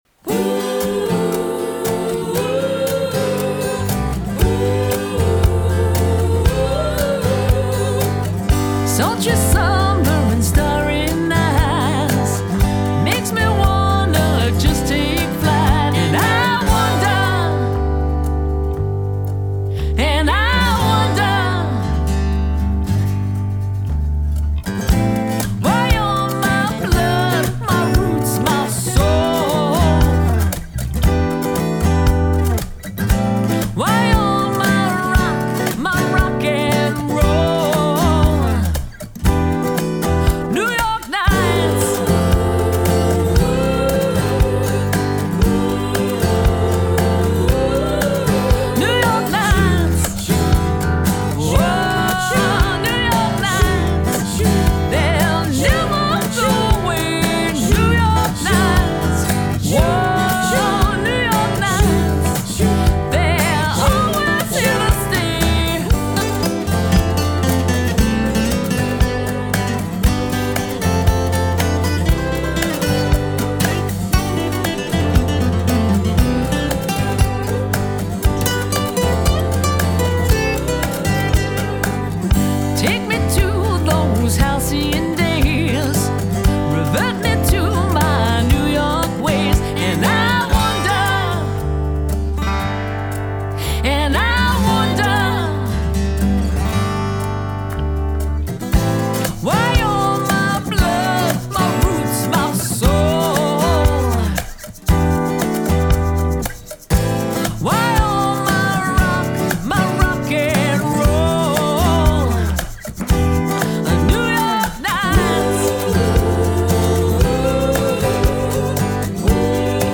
lively, poppy and has the Brill Building feel to it